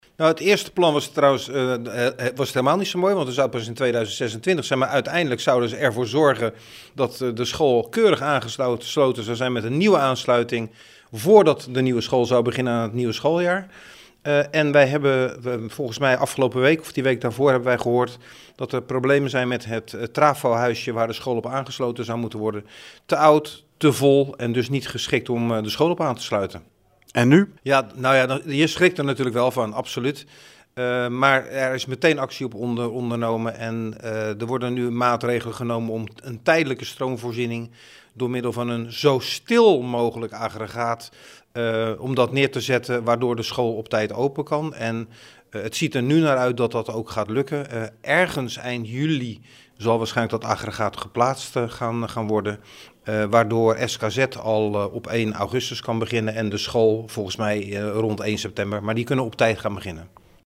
AUDIO: Burgemeester Fred van Trigt over de stroomproblemen rond de Nieuwe Bernardusschool.